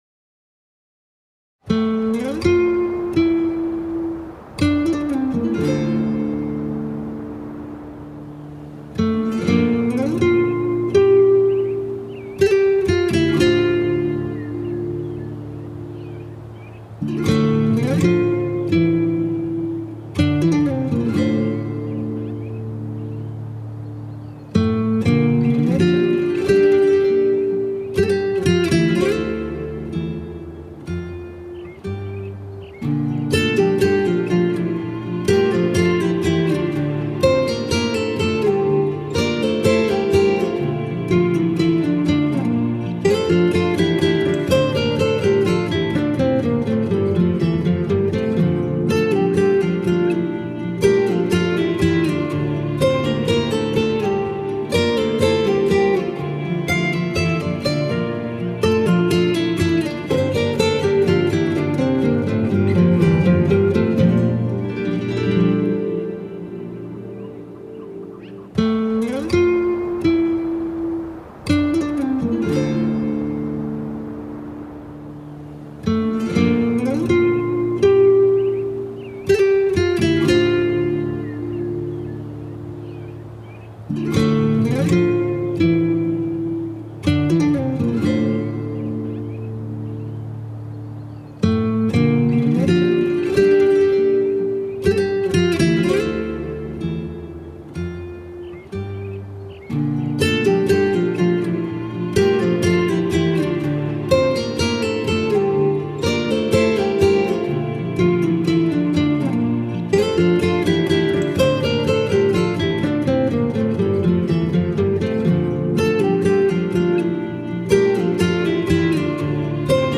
tema dizi müziği, duygusal hüzünlü rahatlatıcı fon müziği.